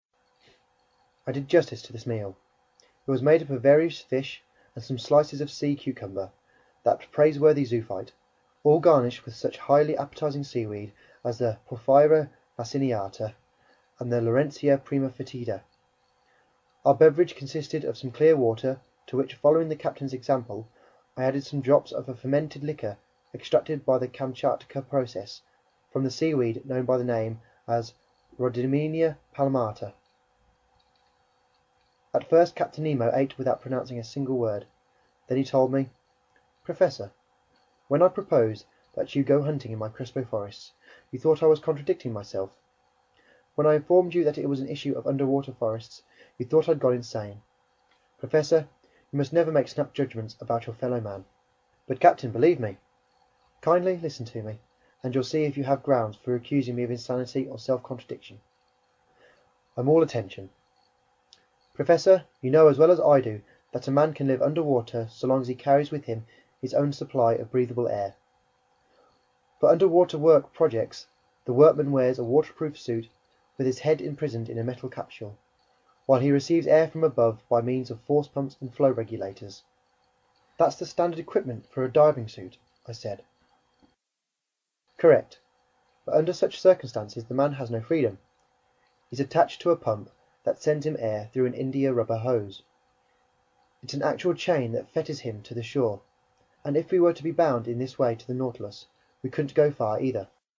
英语听书《海底两万里》第205期 第15章 一封邀请信(7) 听力文件下载—在线英语听力室